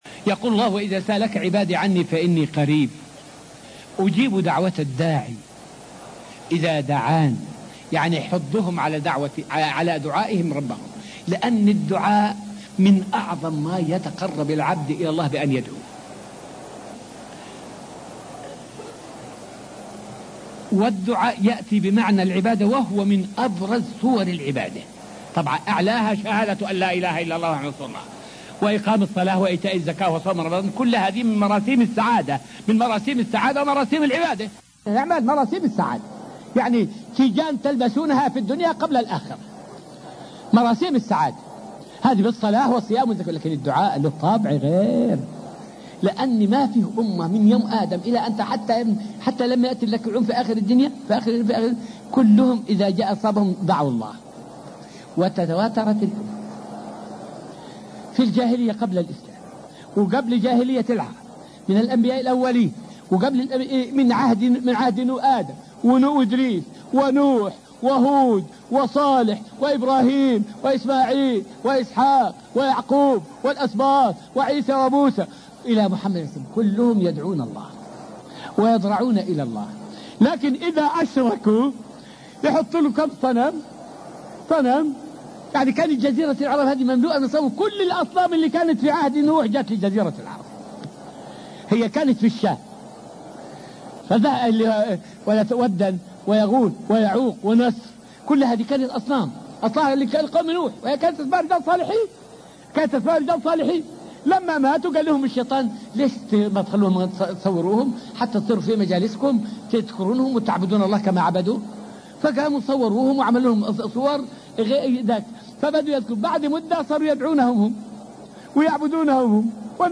فائدة من الدرس الرابع والعشرون من دروس تفسير سورة البقرة والتي ألقيت في المسجد النبوي الشريف حول أن الدعاء من أعظم القربات لله.